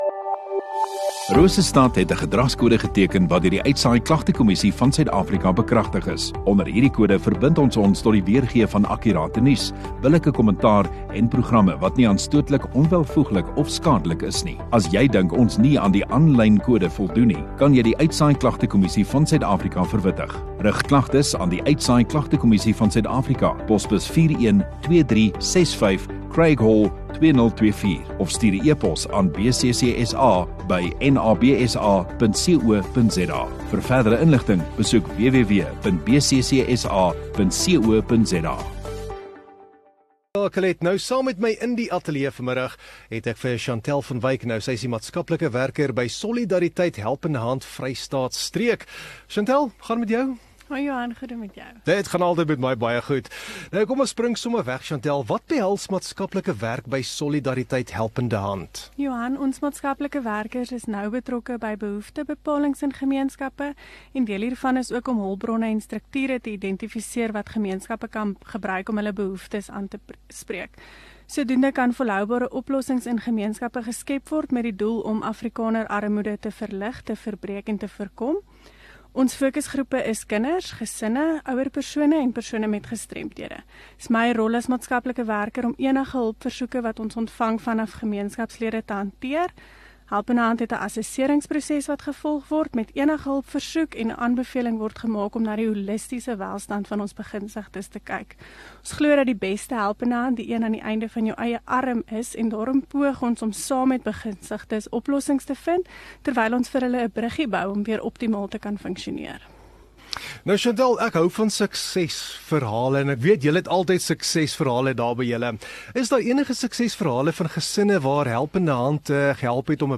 Radio Rosestad View Promo Continue Radio Rosestad Install Gemeenskap Onderhoude 21 Oct Solidariteit